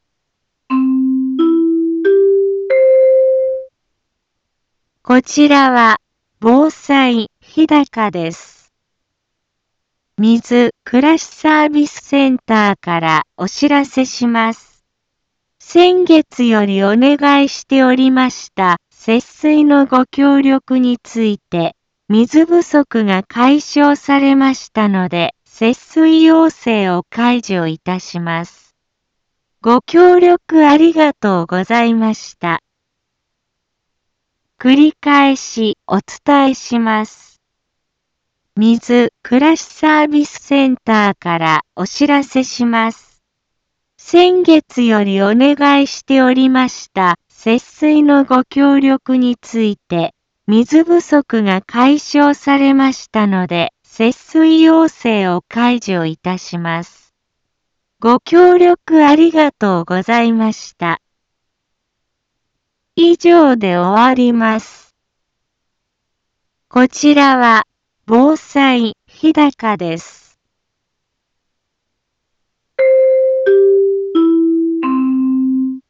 一般放送情報
BO-SAI navi Back Home 一般放送情報 音声放送 再生 一般放送情報 登録日時：2025-03-21 10:02:42 タイトル：節水要請解除のお知らせ インフォメーション： こちらは、防災日高です。